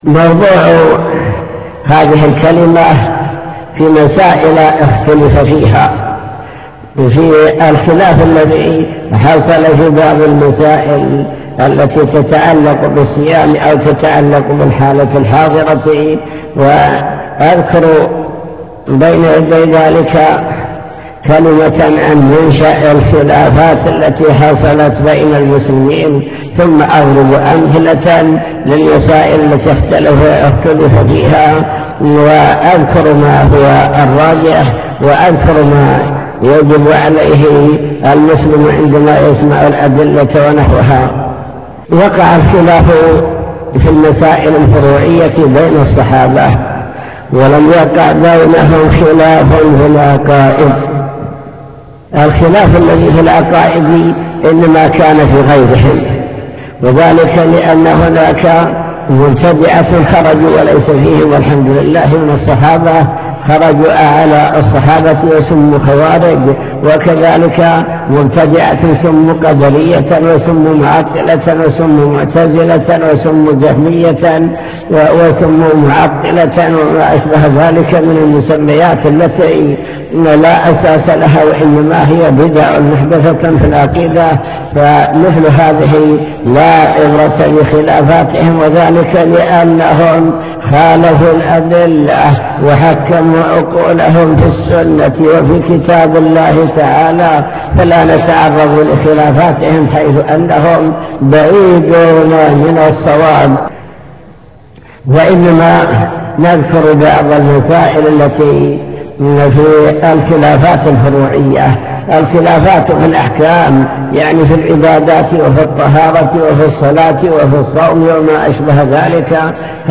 المكتبة الصوتية  تسجيلات - محاضرات ودروس  الافتراق والاختلاف الكلام عن الخلاف وأسبابه